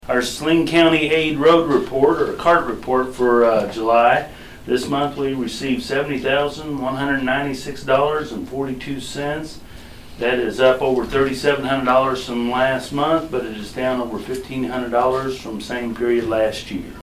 Saline County Treasurer Marty Smith delivered a tax report during the meeting of the county commission on Tuesday, July 23.